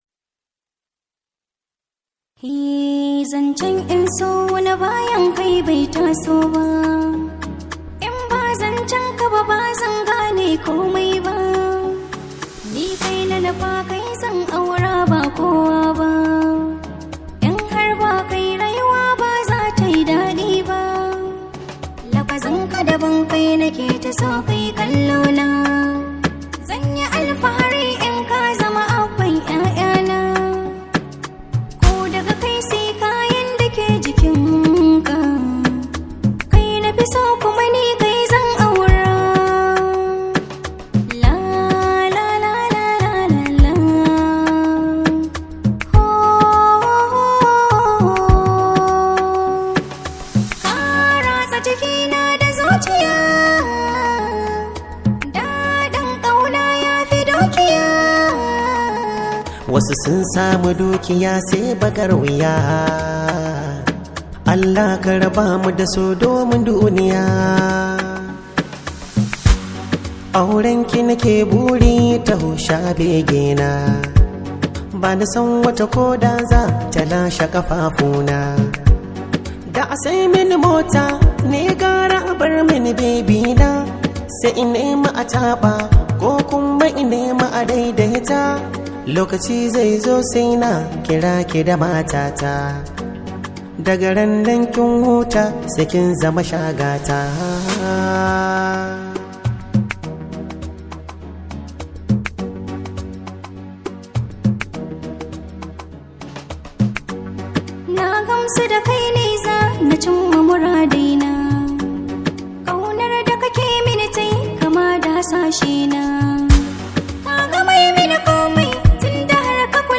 Hausa Songs